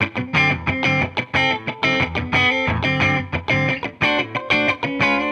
Index of /musicradar/sampled-funk-soul-samples/90bpm/Guitar
SSF_TeleGuitarProc2_90D.wav